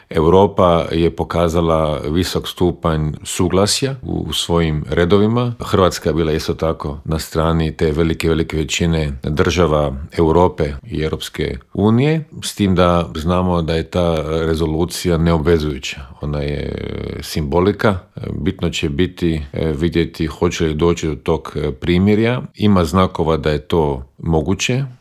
ZAGREB - Bivši ministar vanjskih poslova Miro Kovač gostovao je u Intervjuu Media servisa u kojem se, među ostalim, osvrnuo na rezolucije o Ukrajini koju je Opća skupština Ujedinjenih naroda jučer donijela povodom treće obljetnice rata u toj zemlji.